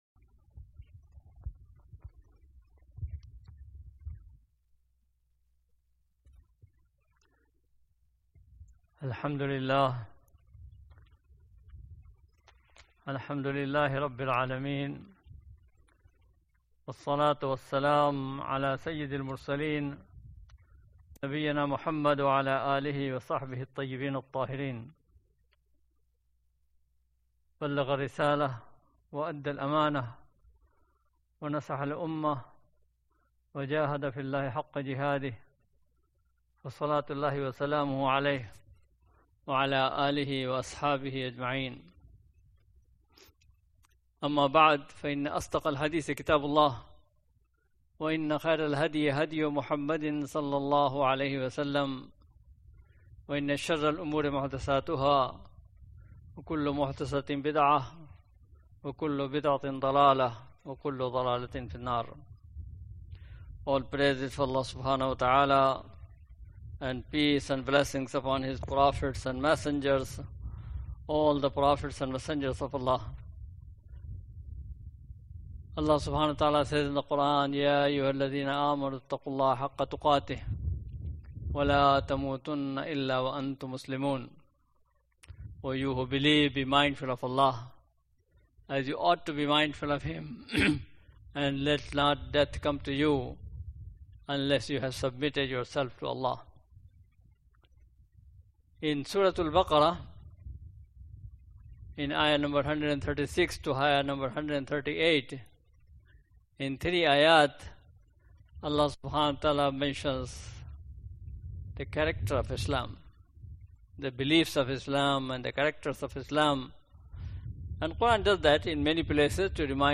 Friday Khutbah - "The Divine Color"